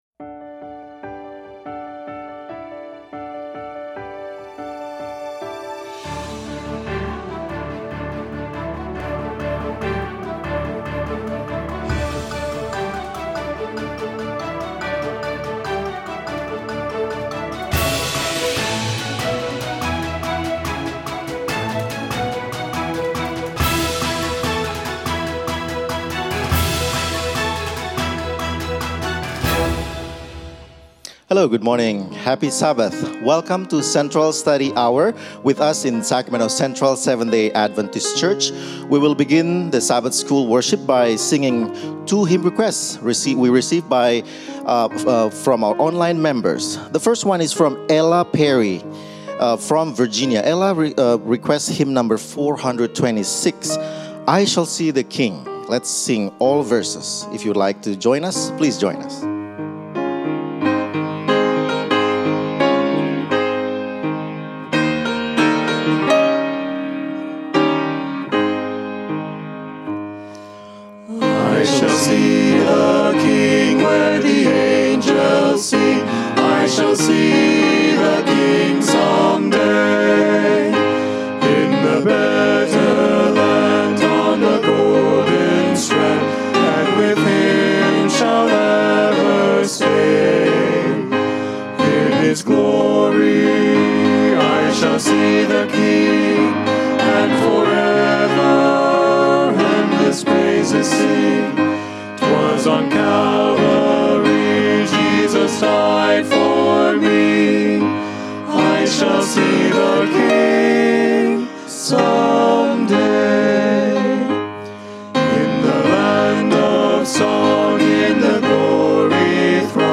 A message from the series "Making Friends For God."